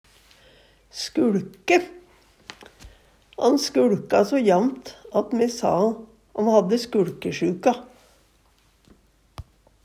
skuLke - Numedalsmål (en-US)